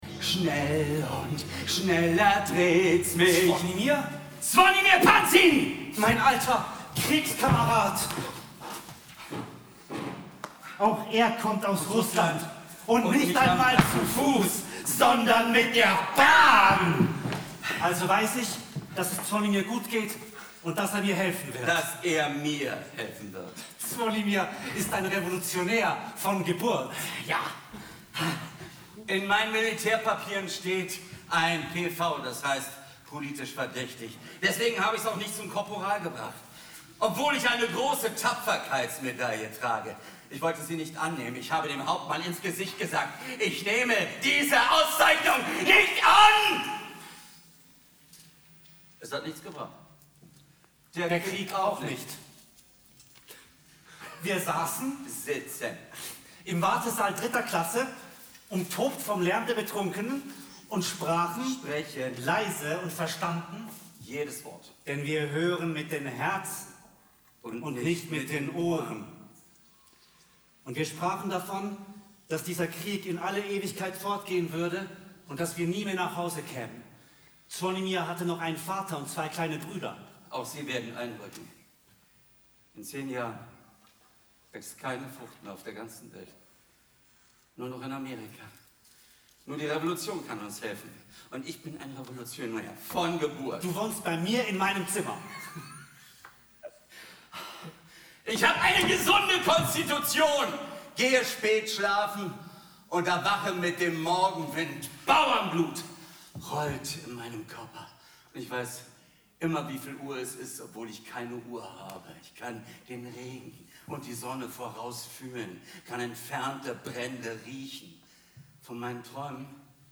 Eine Hybridoperette mit der Musicbanda Franui (2024)